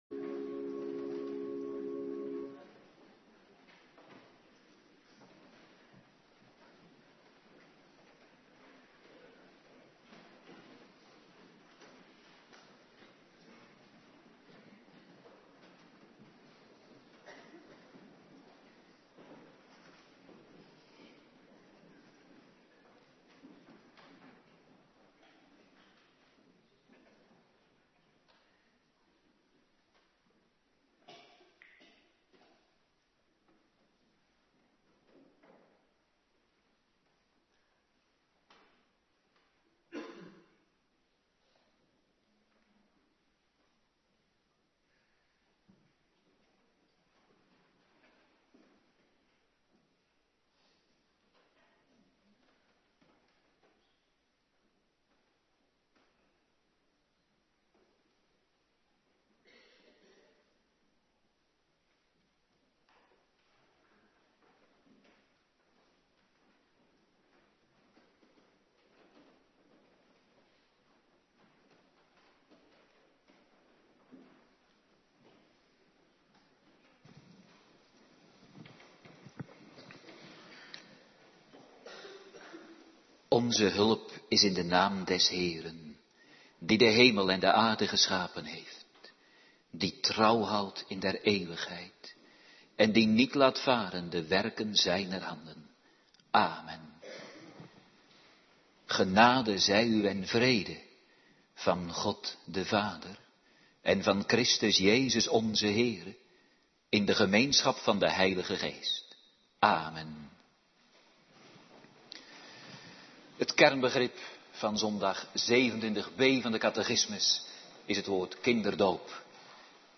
Preken (tekstversie) - Thema - HC zondag 27 | Hervormd Waarder